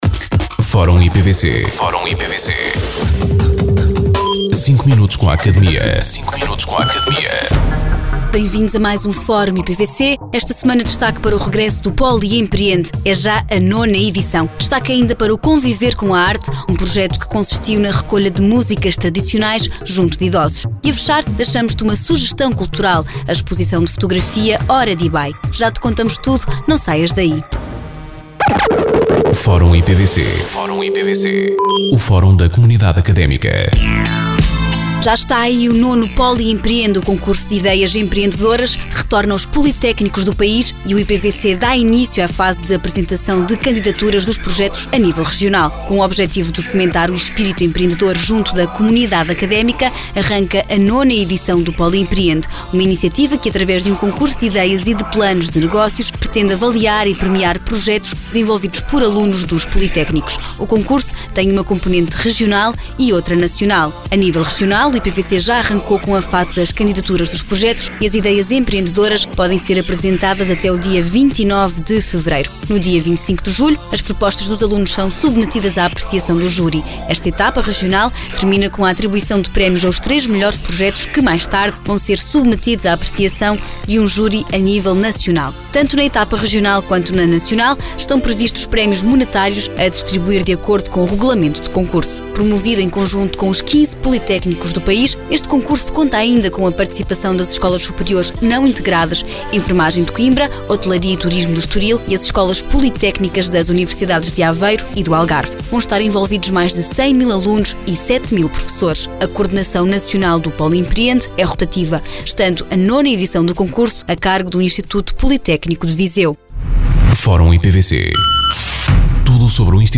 Depois do programa “Academia”, surge assim o “Fórum IPVC”, uma rubrica semanal de cinco minutos, em que são abordadas as notícias e factos mais relevantes da nossa instituição.
Entrevistados: